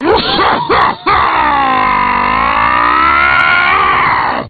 File:Wizpig (crying).oga
Wizpig_(crying).oga.mp3